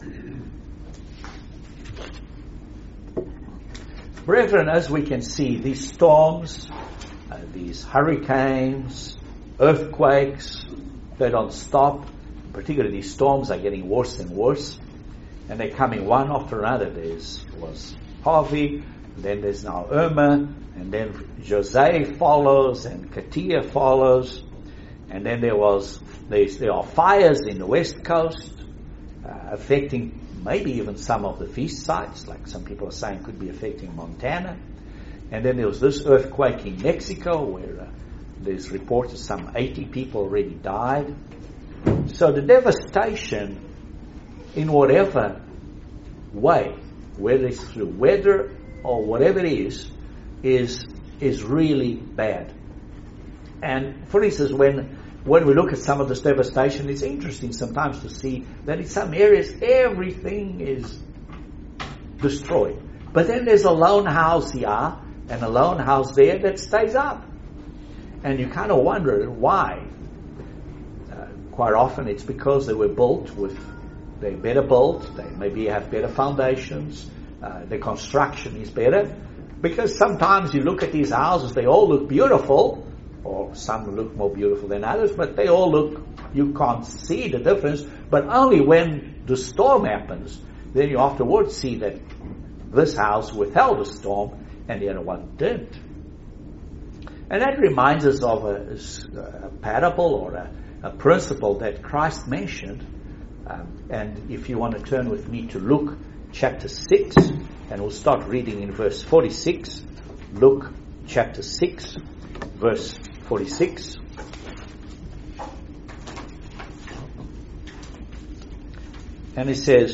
Excellent Sermon on the 4 fall Holy days. Join us for the explanation of the meanings and significance of these holy days.